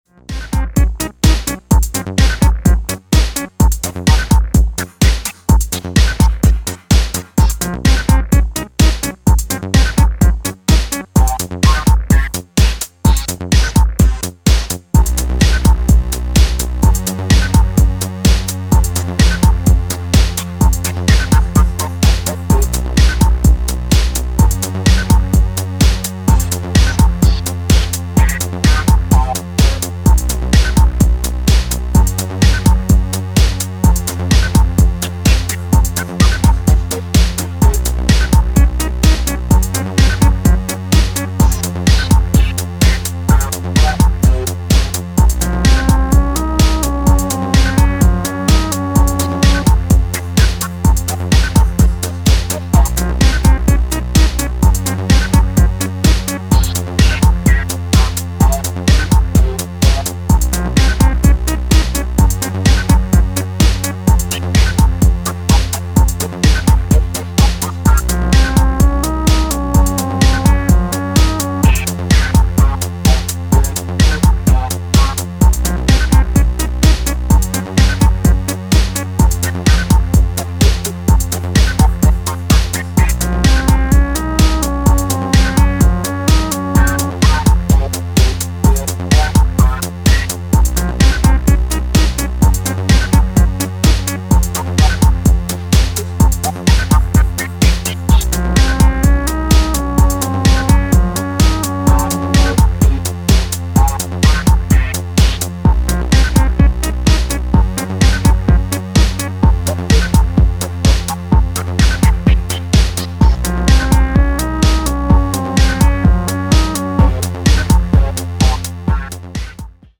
レトロ・フューチャーなEBM・ミーツ・テック・ハウス